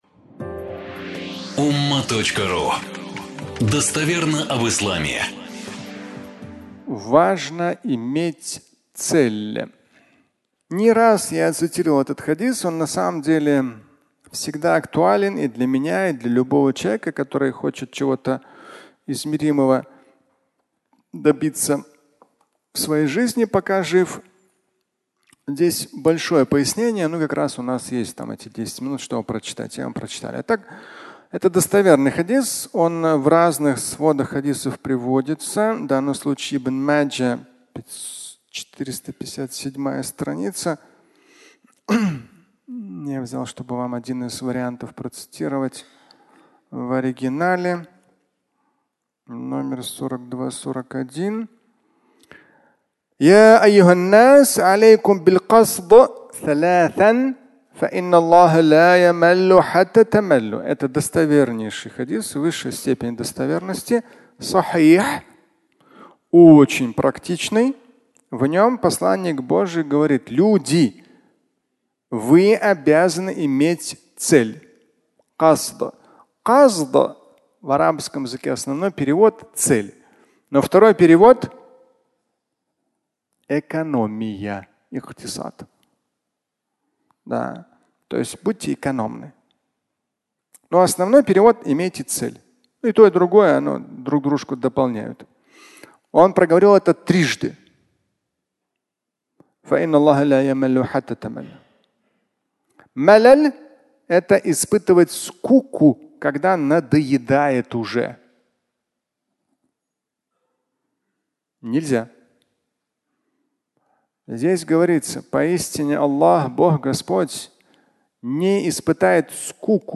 Пятничная проповедь